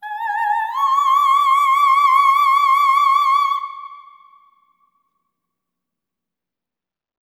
OPERATIC16-L.wav